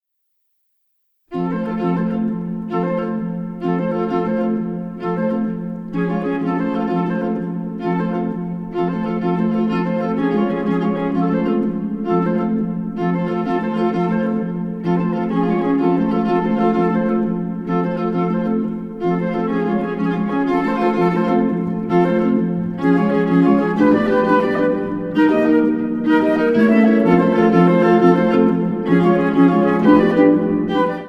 Harp
Flute
Viola Released